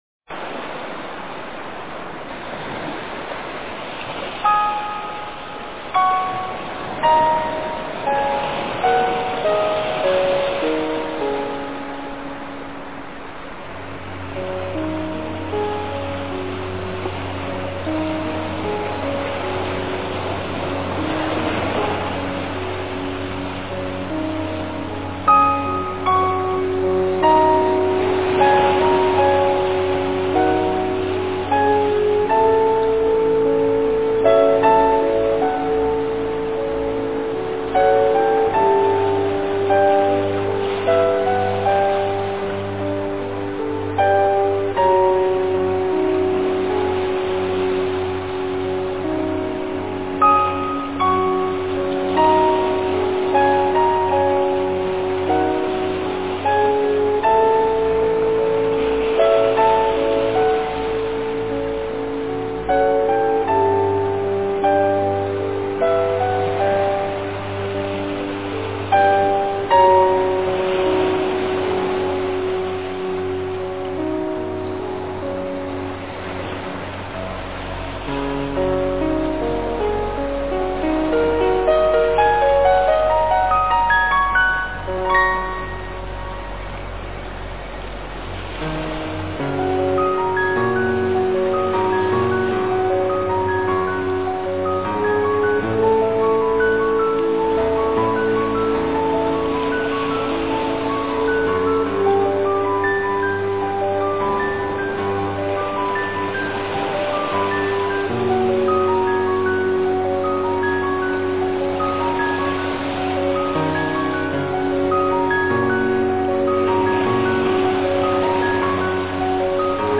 佛音 冥想 佛教音乐 返回列表 上一篇： 寒鸦戏水--范玮卿 下一篇： 達摩禪悟(古筝